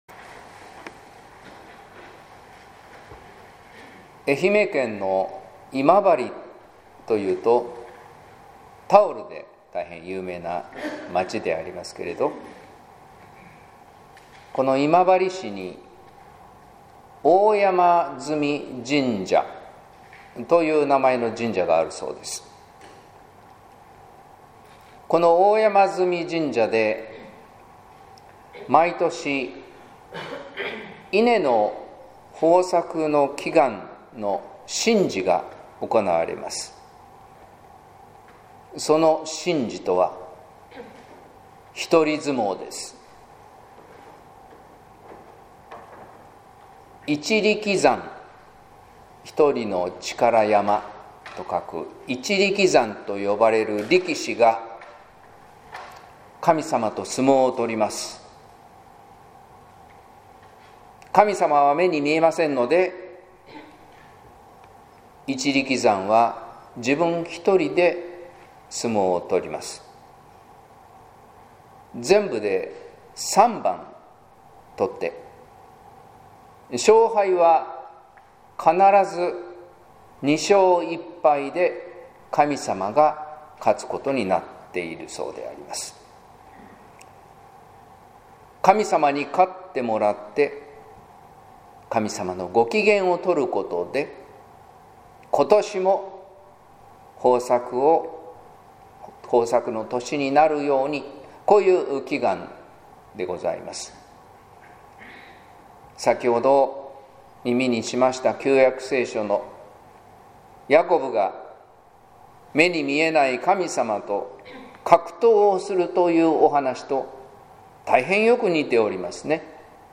説教「ヤコブの一人相撲」（音声版）